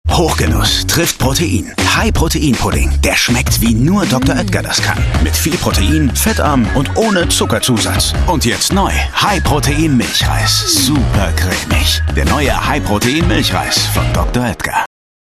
Hörbuch - Infernale